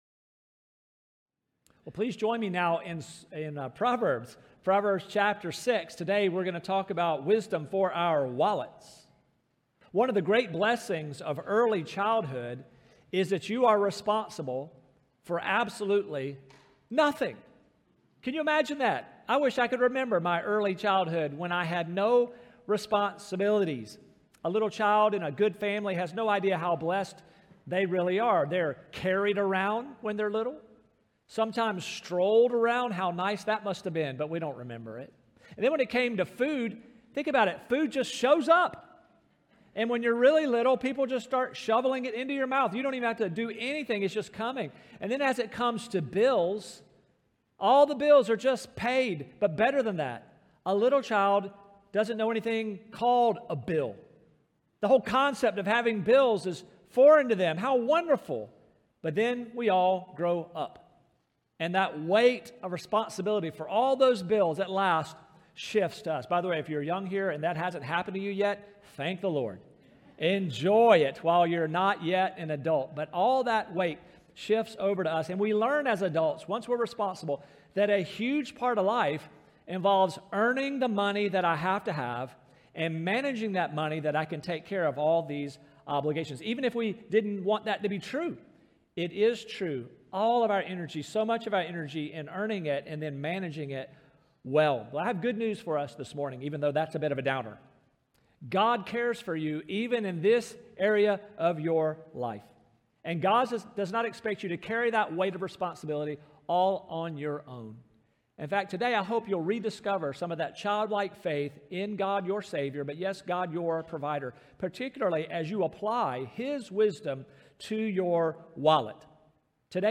Sermons | Staples Mill Road Baptist Church